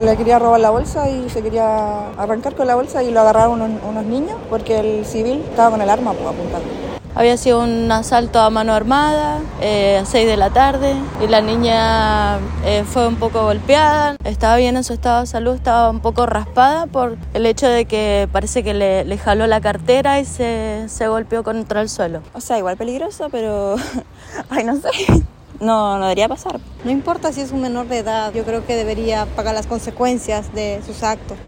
Vecinos relataron lo ocurrido.